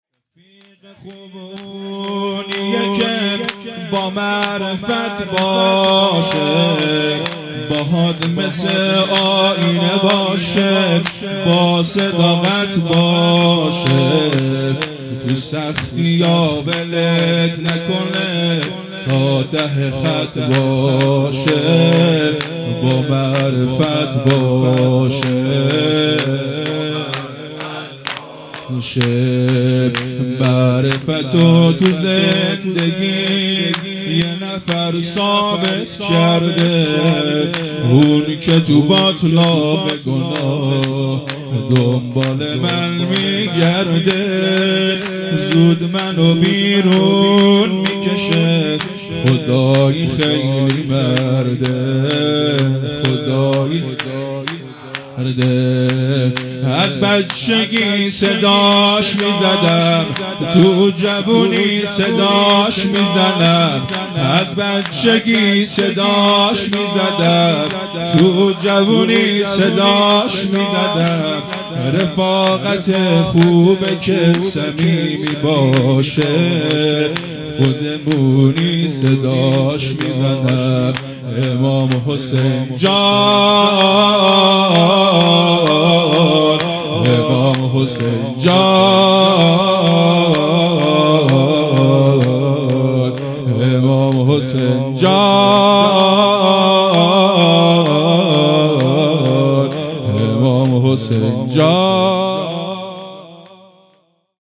شور
شبهای قدر